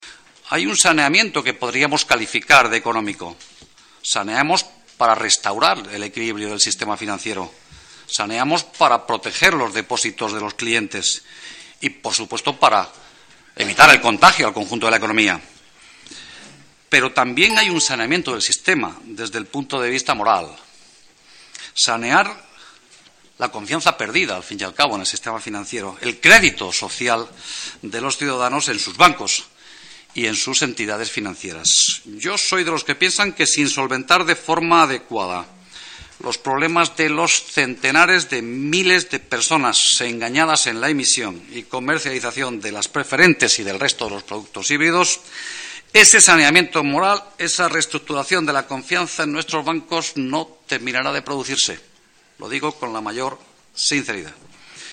Comisión de Economía. Valeriano Gómez 5/02/2014